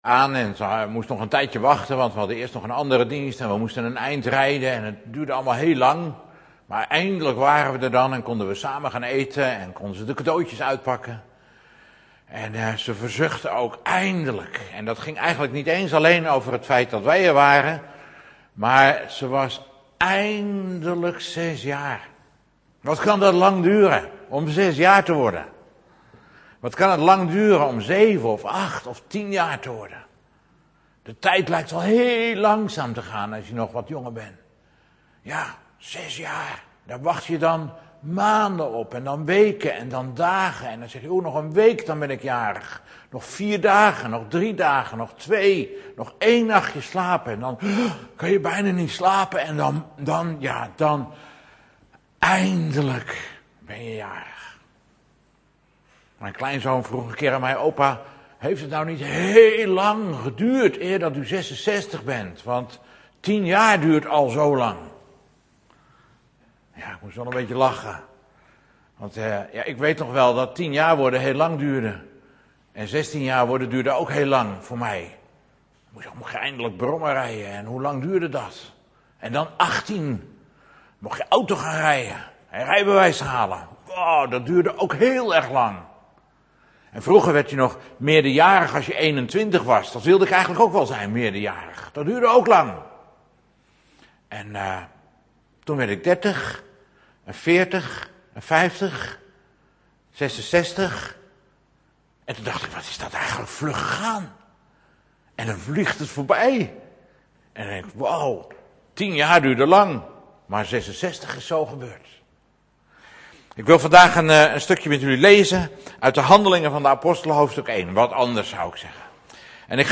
De eerste minuut van deze preek is helaas weggevallen, excuses.